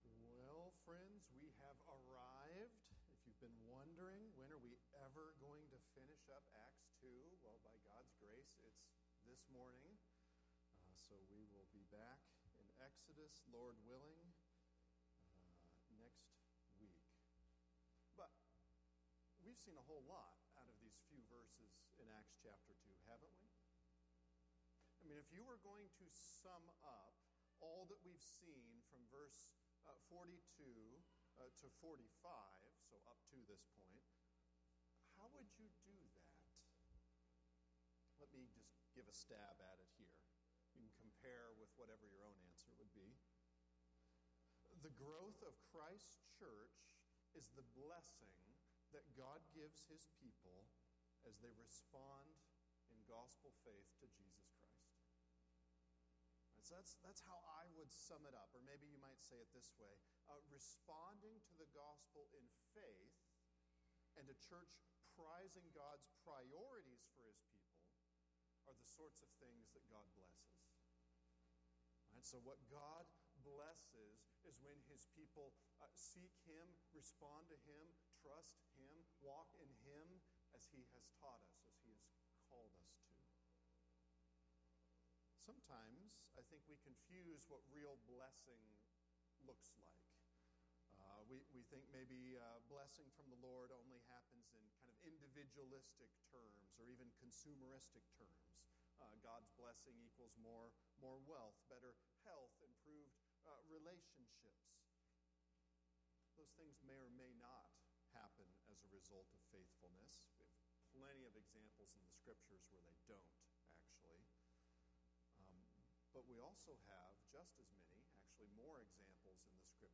Acts 2:46-47 How We Live – Sermons